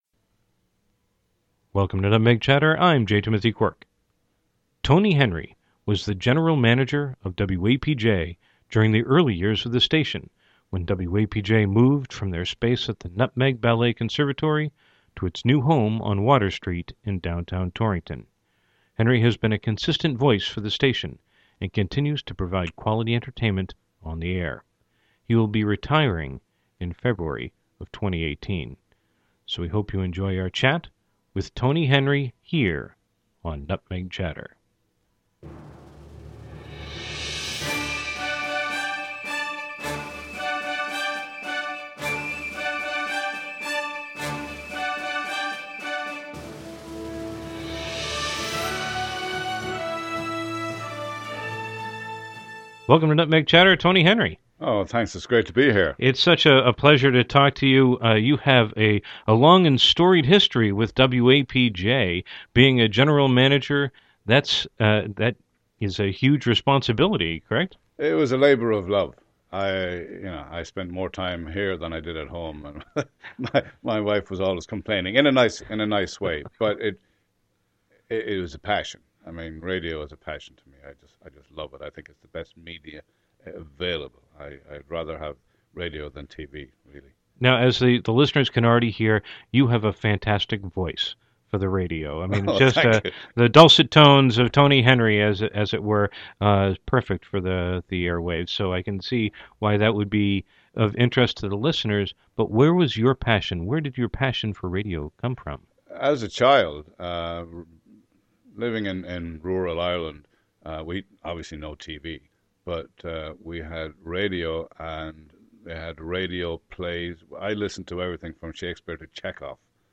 Categories: Radio Show, Torrington Stories